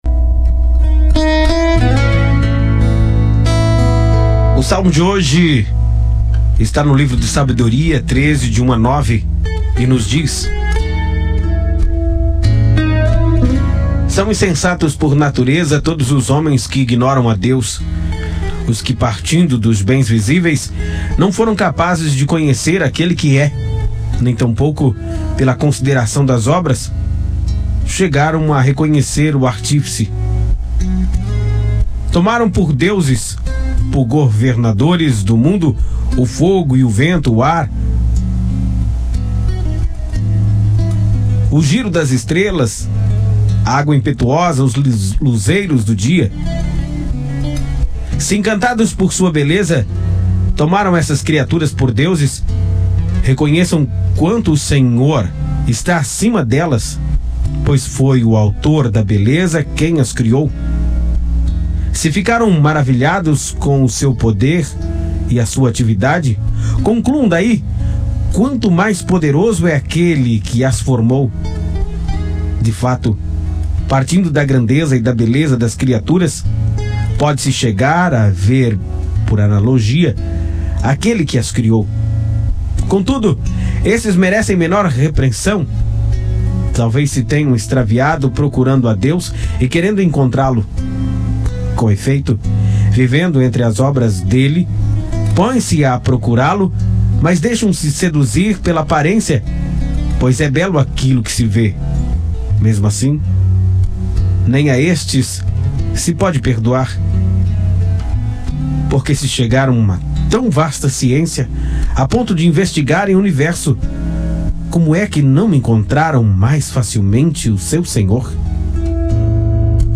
Oração: 'Beleza da criação, encontro com Deus'